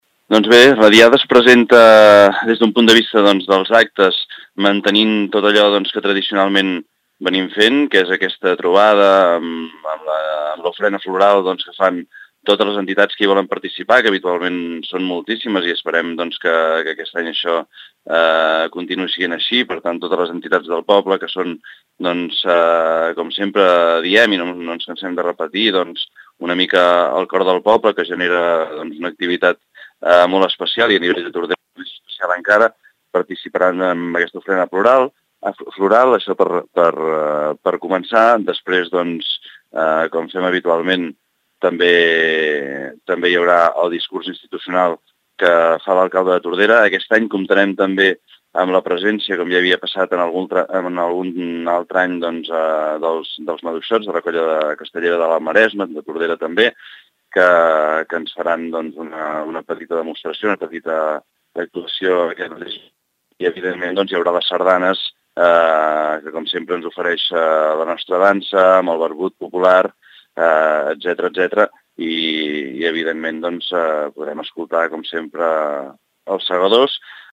Ens detalla les activitats el regidor de cultura, Josep Llorens.